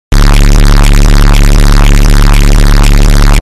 Звуки triggered
Безумный взрыв эмоций